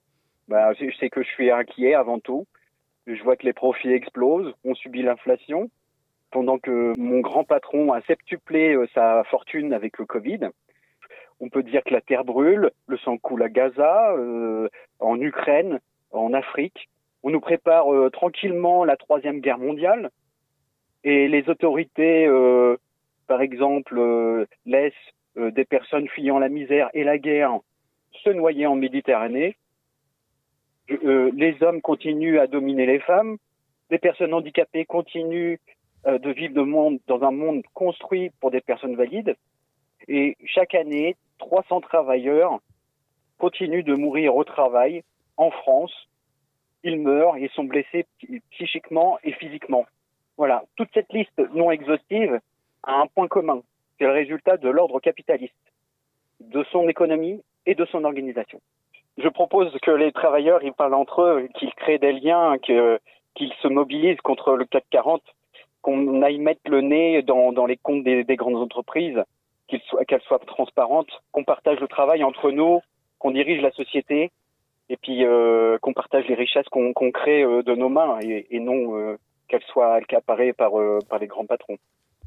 Législatives : les candidats du Faucigny (interviews)
Voic les interviews des 5 candidats de cette 3eme circonscription de Haute-Savoie (par ordre du tirage officiel de la Préfecture) et tous les candidats en Haute-Savoie et en Savoie.